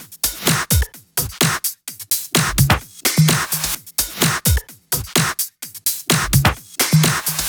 VFH2 128BPM Capone Kit 3.wav